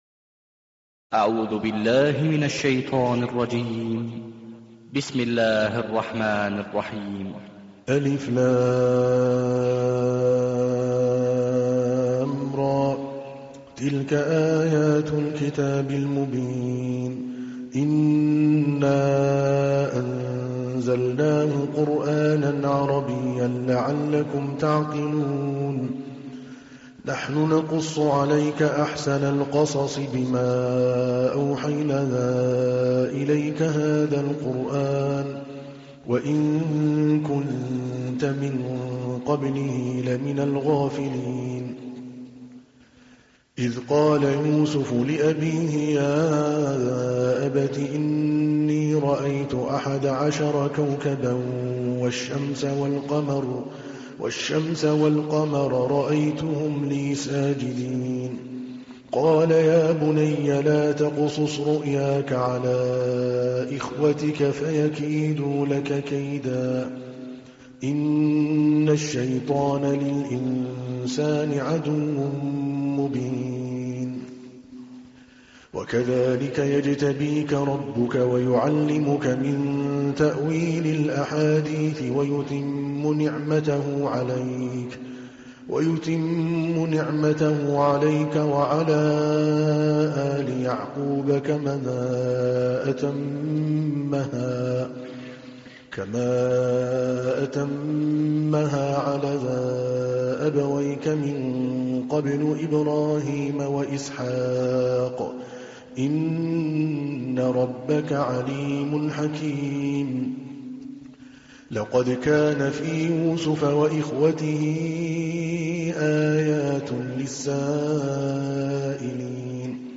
تحميل : 12. سورة يوسف / القارئ عادل الكلباني / القرآن الكريم / موقع يا حسين